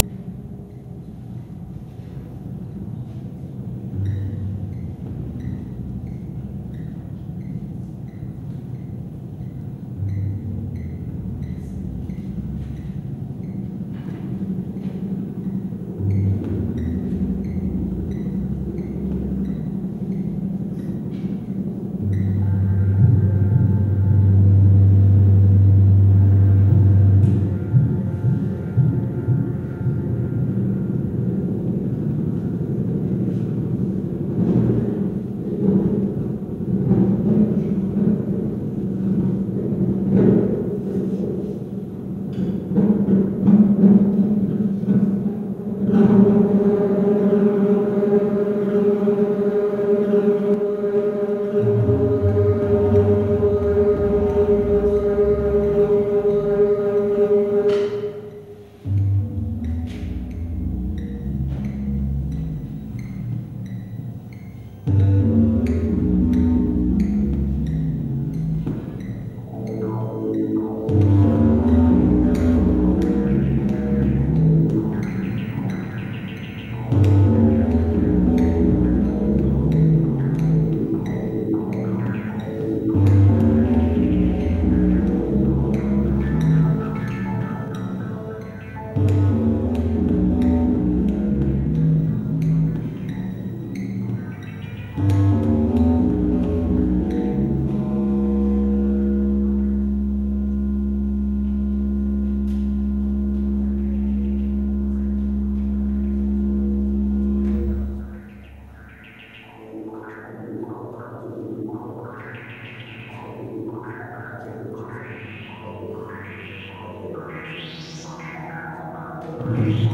Live in Bologna
All files are 64kb/s, MONO audio.